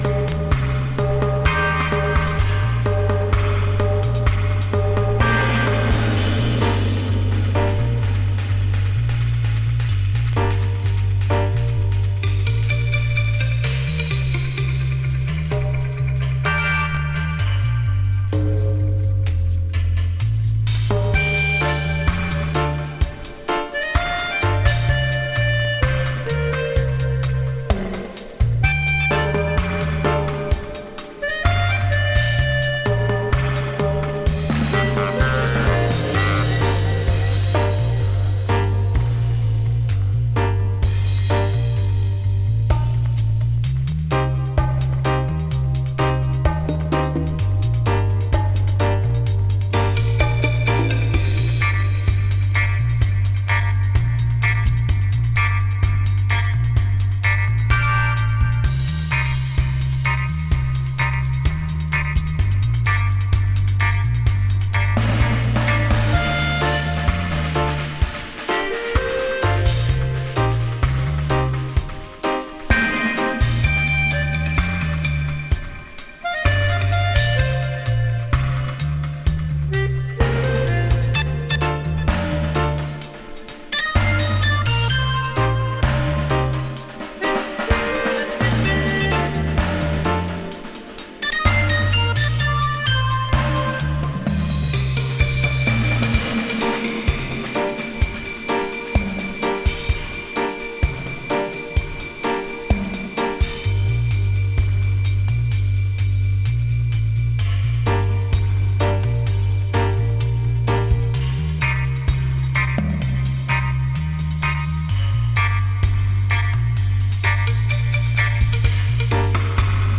大迫力のベース、炸裂するスネアの存在感が強い
どちらもオーセンティックな佇まいがナイス、じっくりハマる新古典派ダブ傑作です。